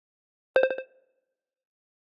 Звуки MacBook Pro и iMac скачать mp3 - Zvukitop